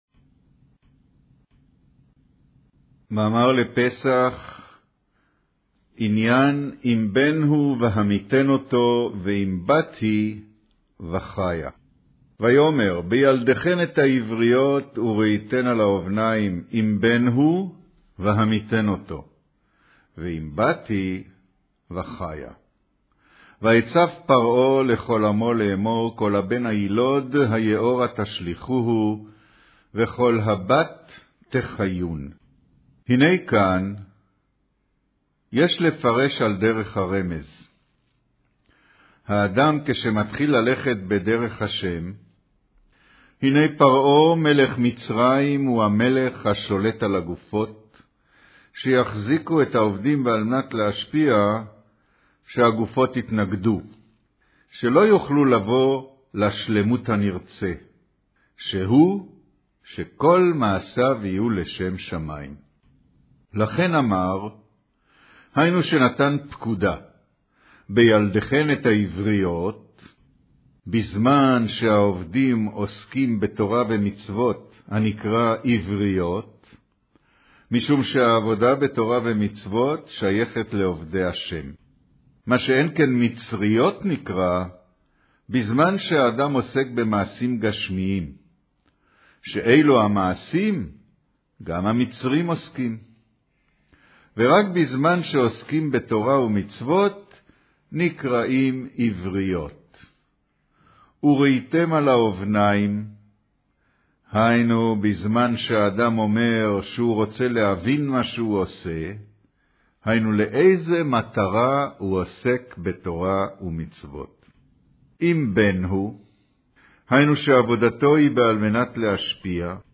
קריינות מאמר ויאמר בילדכן את העבריות ...ויצו פרעה לכל עמו לאמר